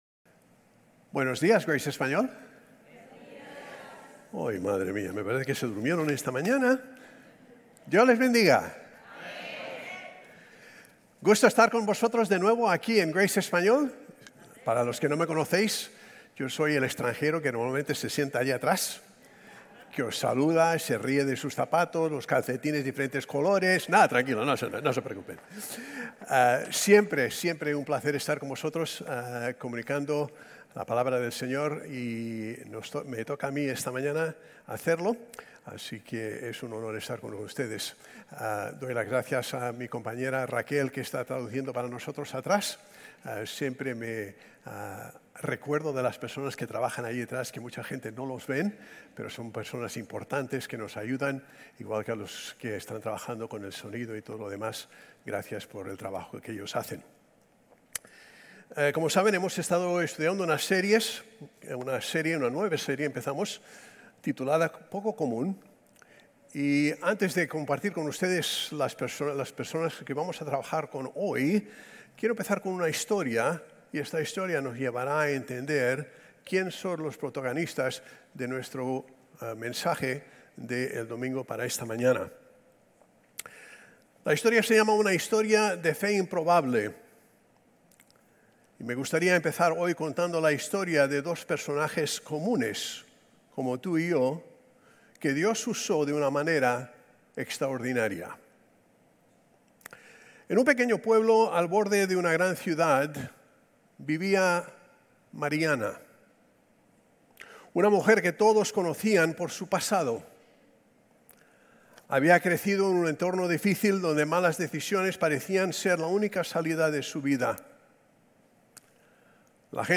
Sermones Grace Español 6_29 Grace Espanol Campus Jul 01 2025 | 00:35:32 1x 00:00 / 00:35:32 Subscribe Share RSS Feed Share Link Embed